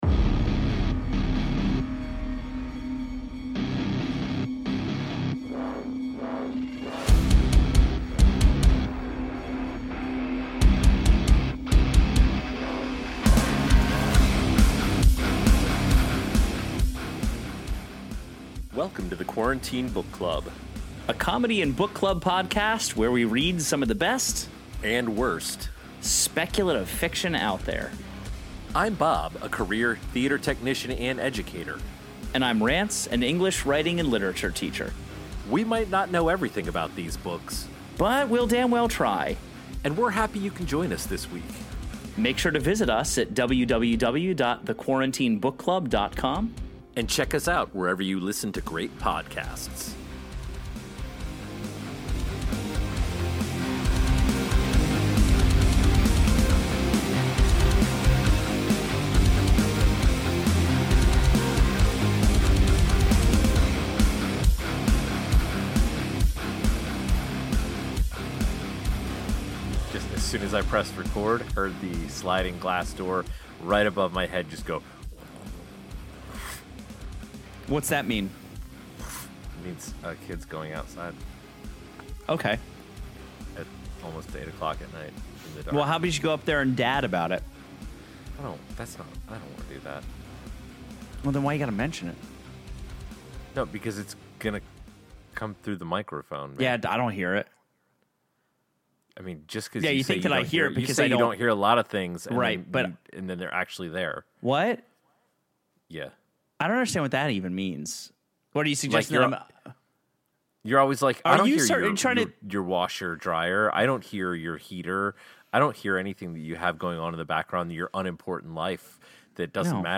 This week the guys discuss The Land: Founding, Chaos Seeds: Book 1, a LitRPG Saga by Aleron Kong. Our hosts have a hard time picking a subject and staying on track, and come to a general consensus that this is, in fact, a book.